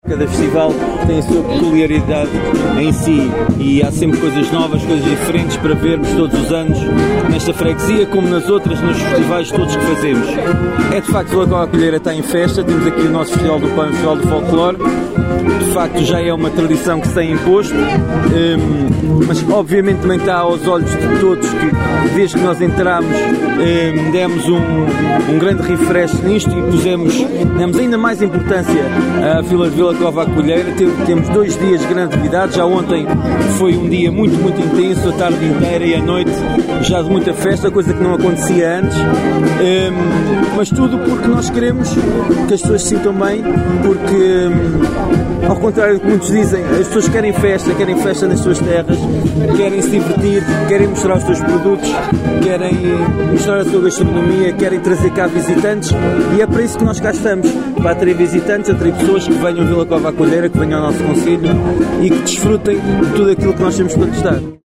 Paulo Marques, Presidente do Município de Vila Nova de Paiva, disse que este Festival do Pão já é uma tradição, “queremos atrair mais visitantes…”.